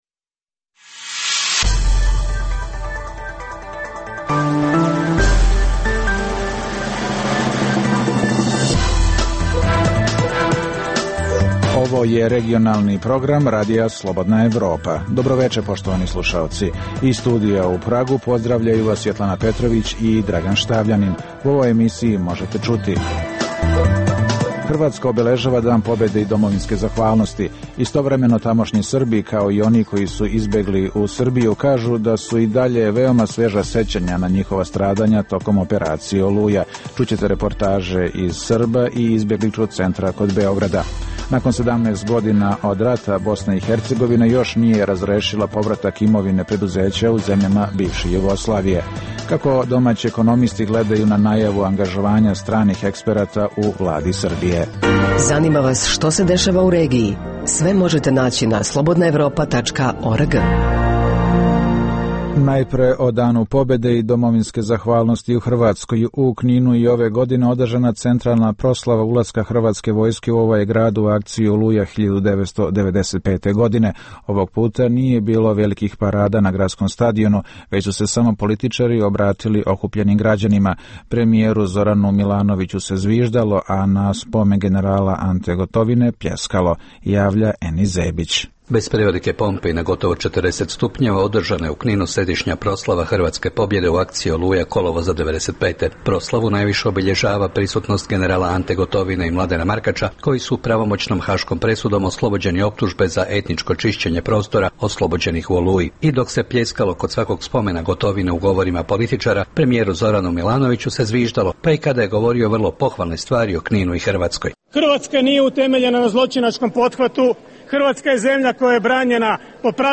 Čućete reportažu iz Srba i izbegličkog centra kod Beograda.